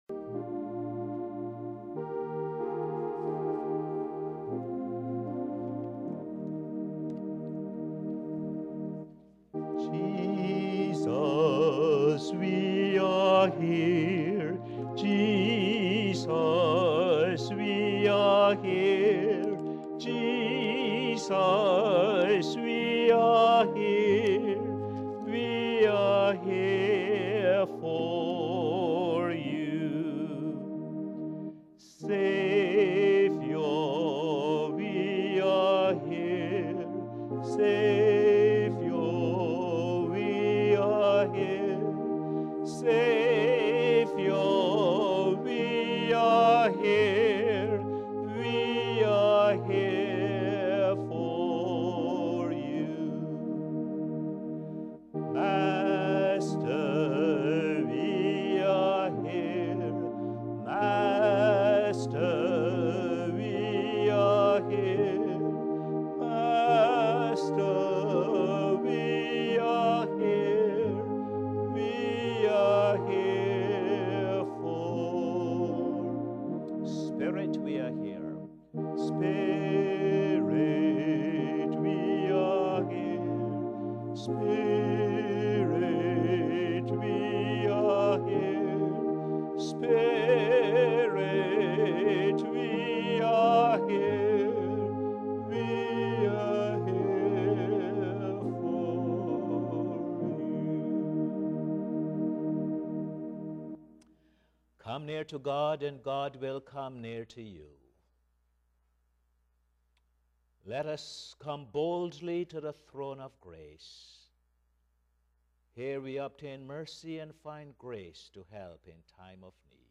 Posted in Sermons on 22. Feb, 2012